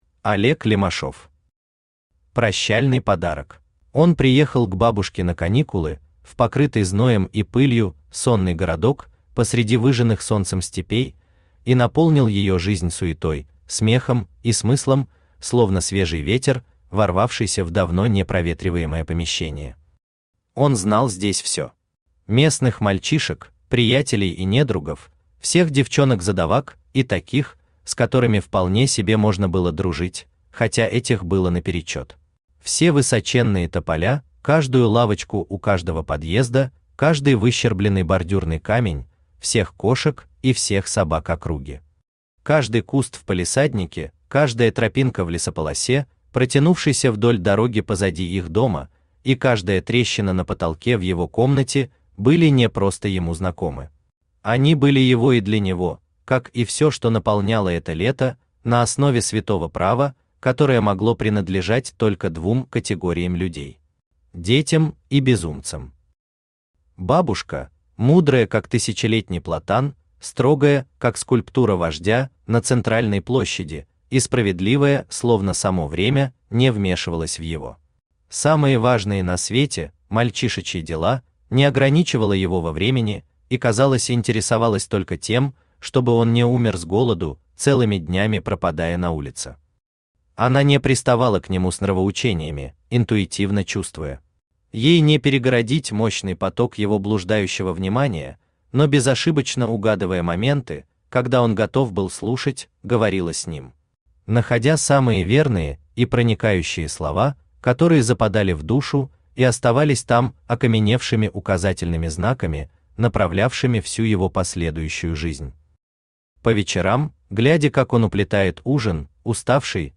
Аудиокнига Прощальный подарок | Библиотека аудиокниг
Aудиокнига Прощальный подарок Автор Олег Лемашов Читает аудиокнигу Авточтец ЛитРес.